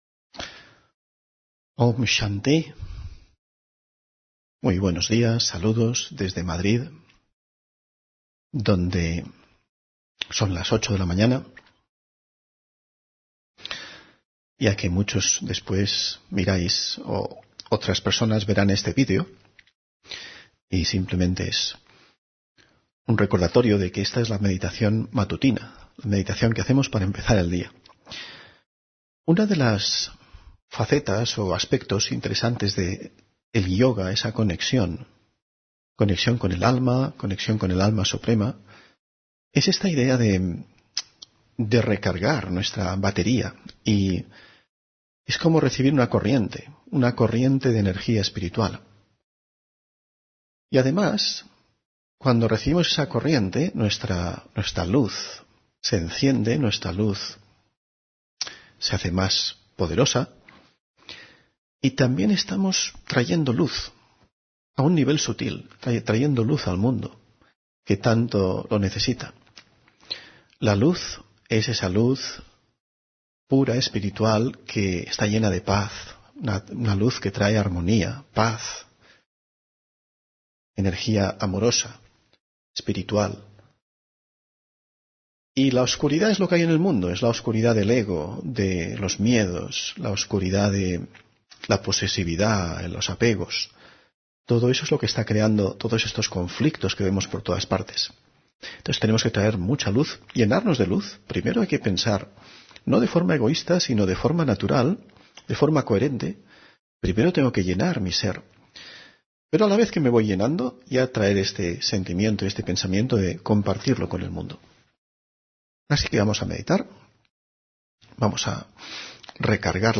Audio conferencias